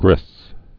(grĭth)